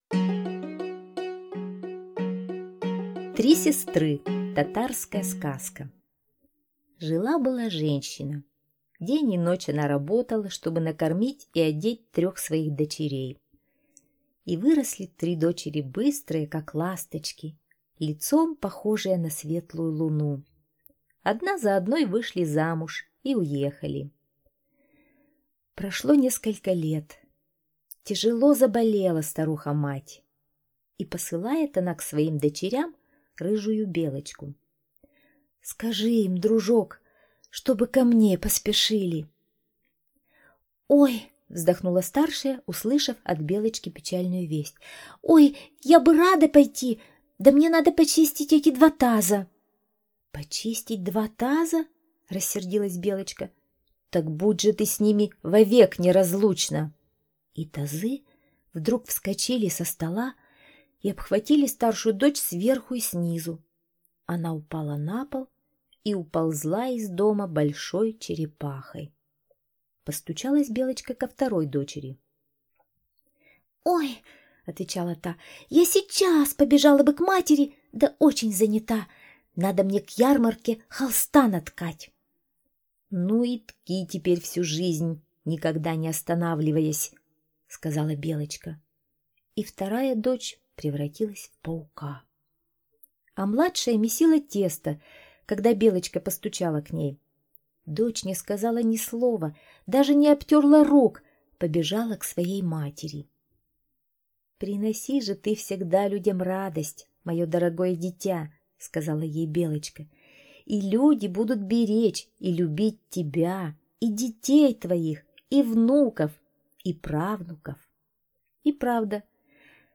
Три сестры - татарская аудиосказка - слушать онлайн